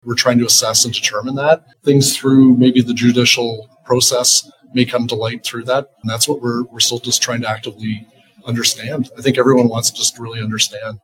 At a press conference today Norfolk County leaders commended first responders and the community for their efforts during a challenging series of fires.